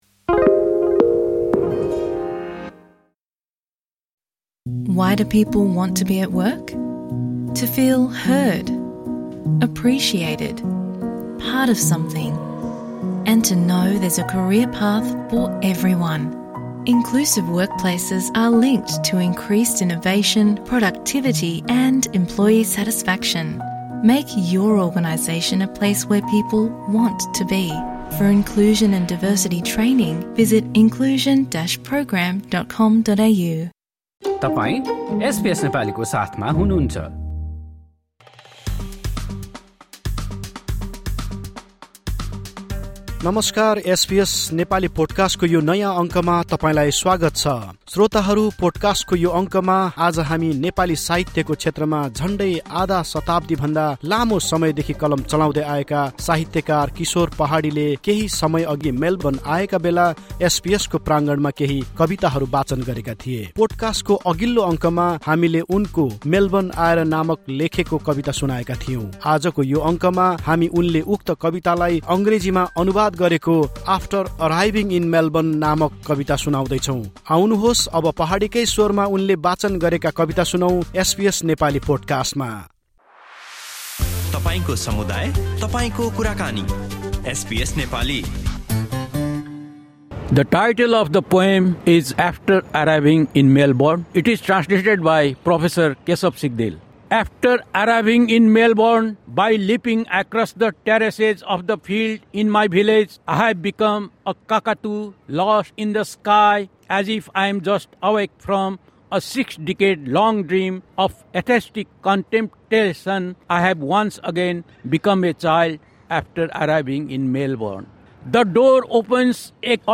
कविता वाचन
reciting his poem on Friday, 12 September 2025 at the Federation Square, Melbourne.